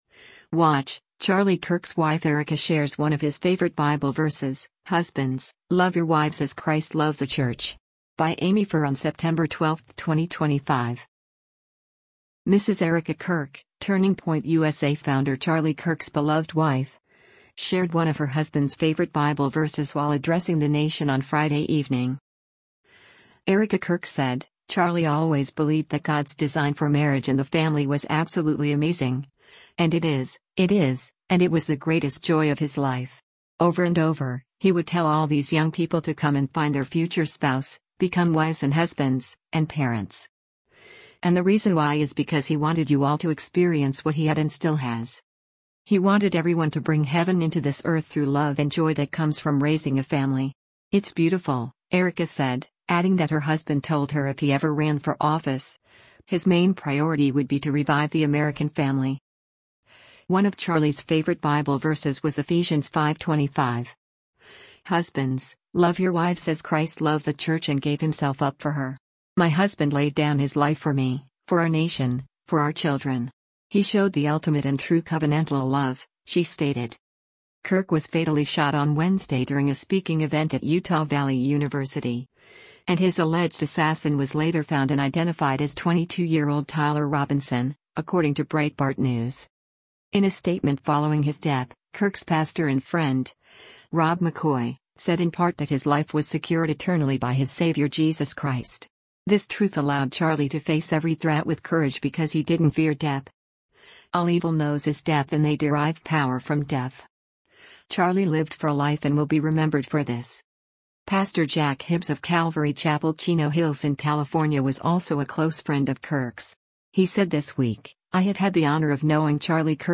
Mrs. Erika Kirk, Turning Point USA founder Charlie Kirk’s beloved wife, shared one of her husband’s favorite Bible verses while addressing the nation on Friday evening.